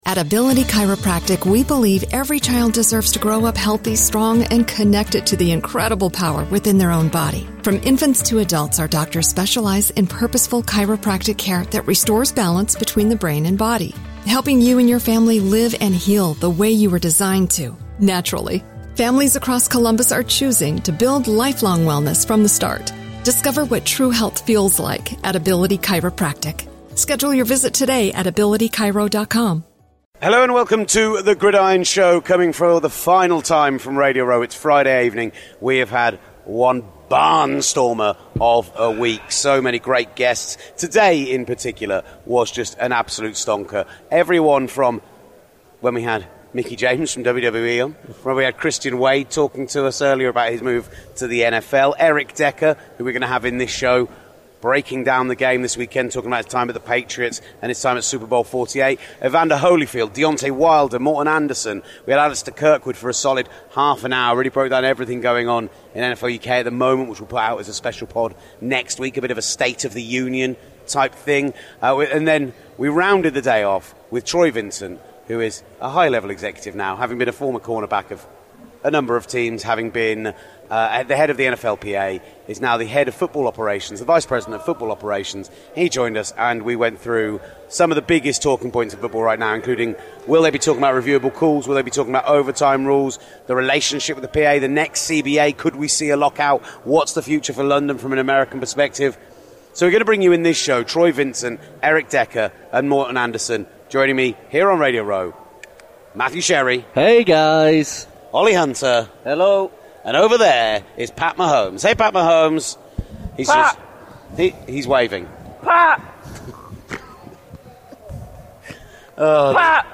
Former Broncos receiver Eric Decker (20:47) tells us about his time in New England and compares what it's like having Tom Brady or Peyton Manning vs almost any other Quarterback. Legendary Kicker Morten Andersen (36:55) discusses his overtime winning FG to take the Falcons to their first Super Bowl. And we have an Exclusive interview with Executive Vice President of Football Operations for the NFL Troy Vincent (46:02) on the CBA, officiating in the NFL, the games coming to London and much, much more!